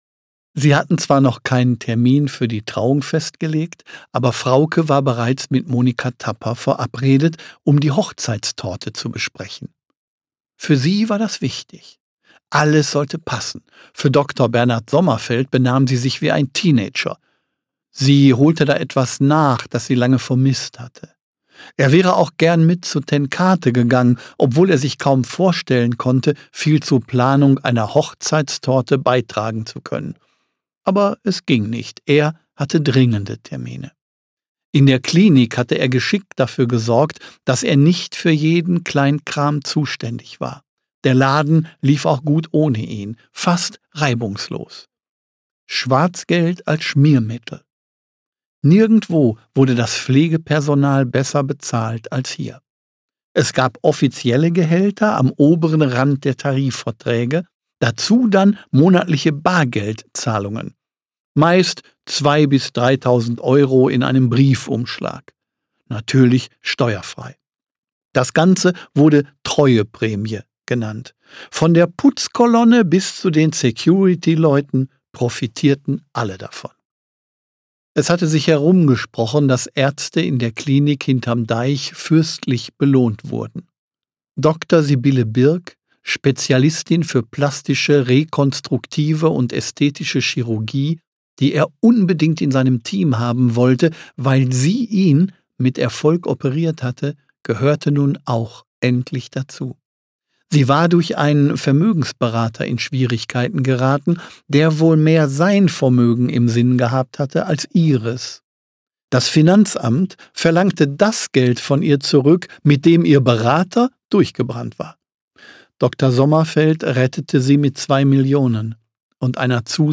Klaus-Peter Wolf (Sprecher)